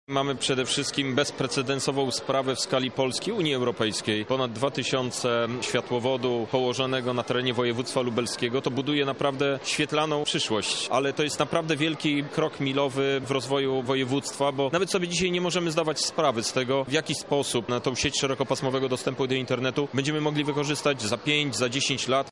– mówi Krzysztof Hetman, europoseł i były marszałek województwa lubelskiego